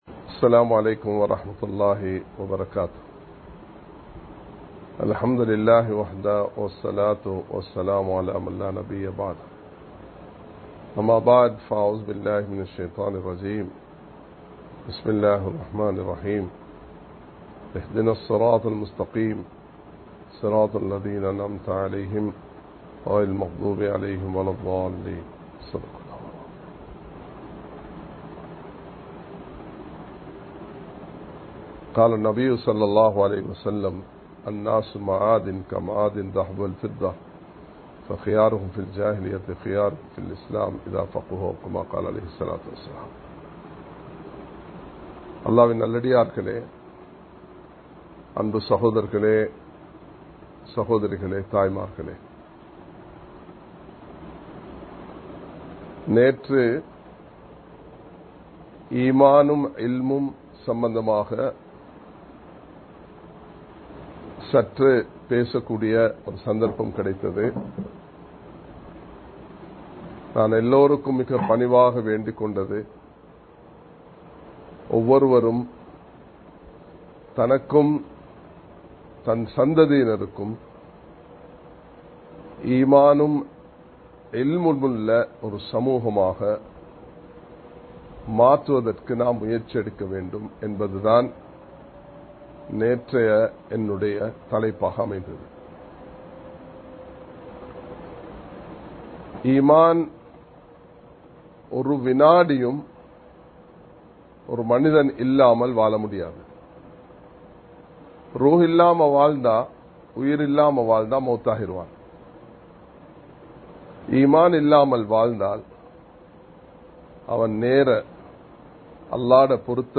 நபியவர்களின் வழிகாட்டல்கள் (Guidelines of the Prophets) | Audio Bayans | All Ceylon Muslim Youth Community | Addalaichenai
Live Stream